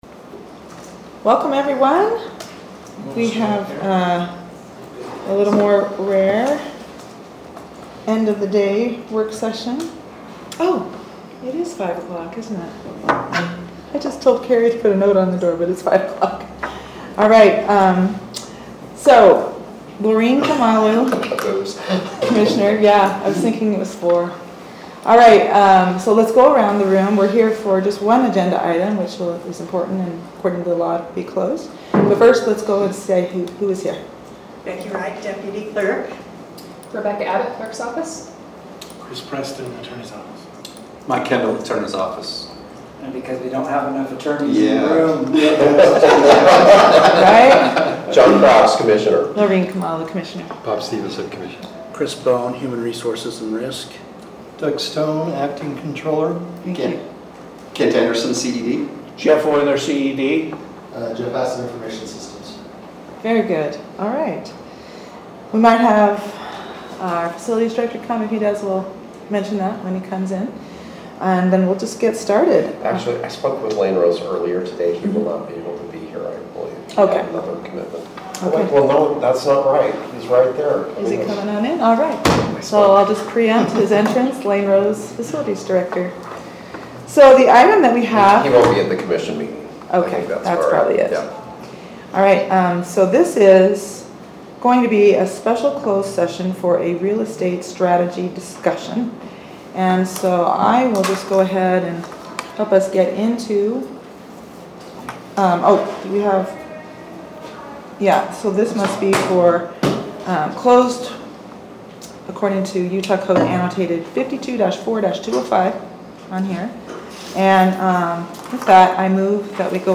Meeting
61 S Main Street, Room 306